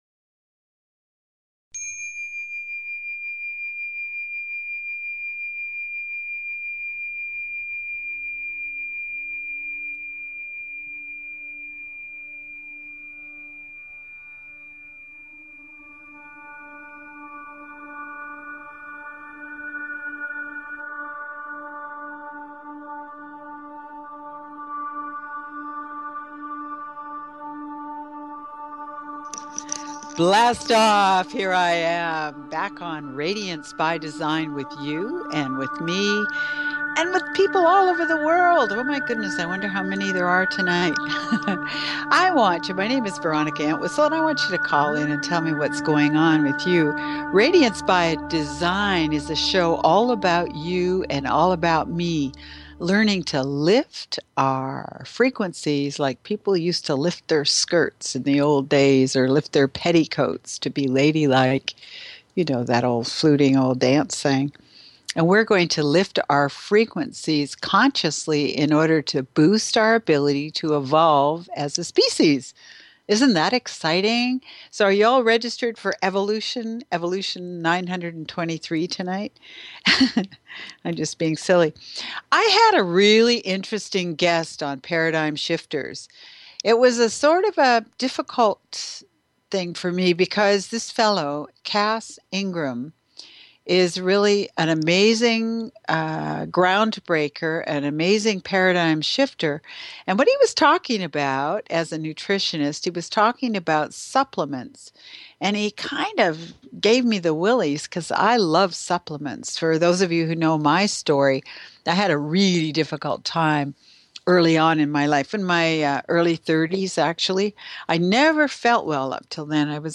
Radiance By Design is specifically tailored to the energies of each week and your calls dictate our on air discussions.
During the show, callers (and listeners) can feel the transformative energy directly through the airwaves.